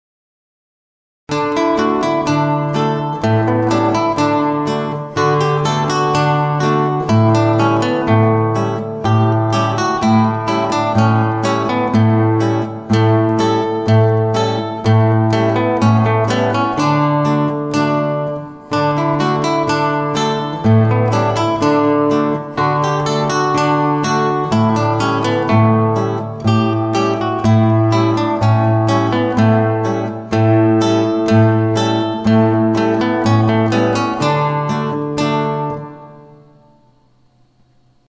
Gitarre, akustische Gitarre
Klassischer Stil